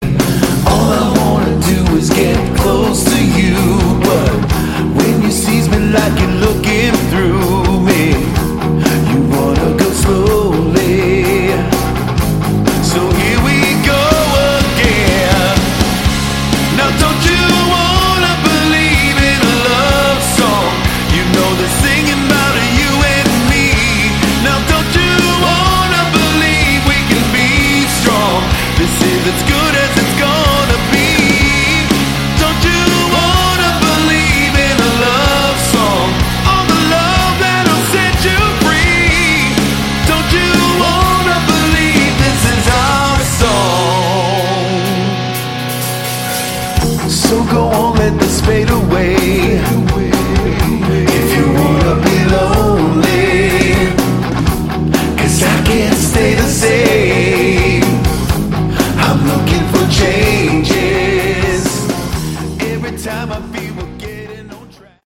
Category: AOR
guitar and vocals
bass and vocals
drums and vocals